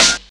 Snare (39).wav